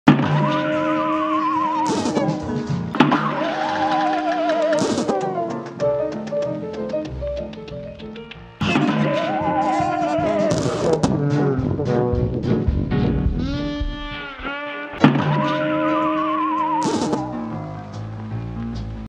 Cuphead Death Sound